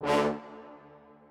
strings12_1.ogg